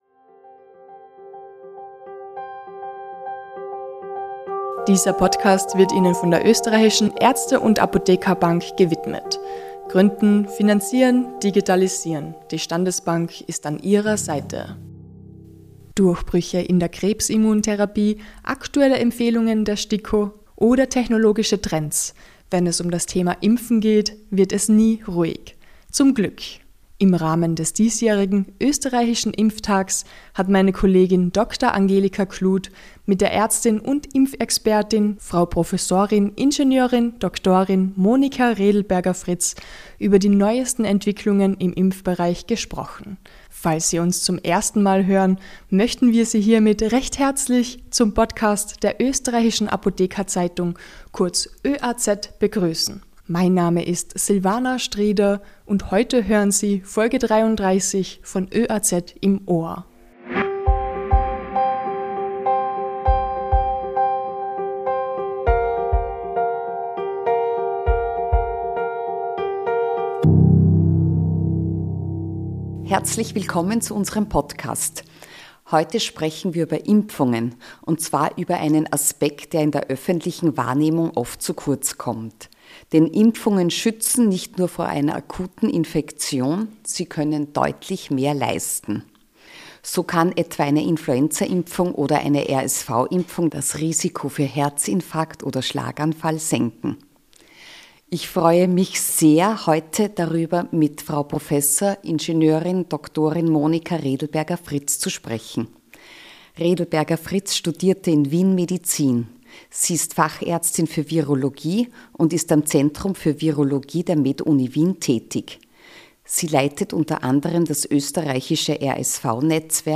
Das Gespräch